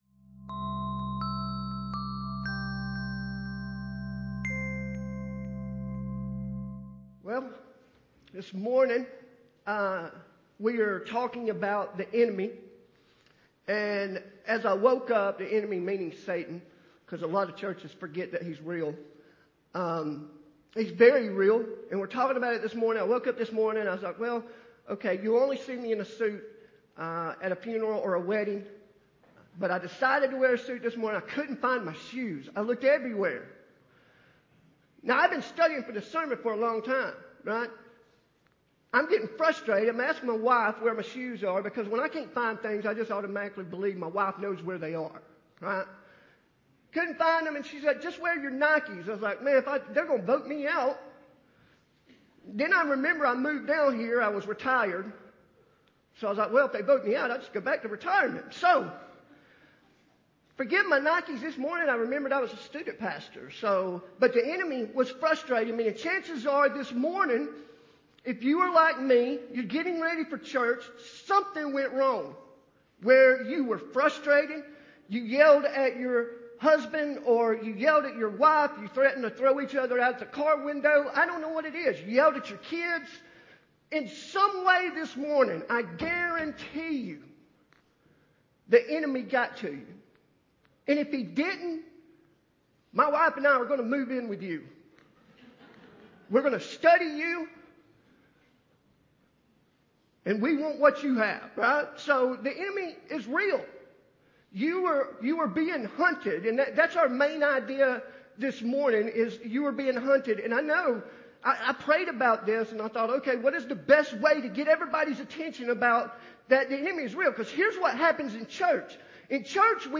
Sermon-26.4.12-CD.mp3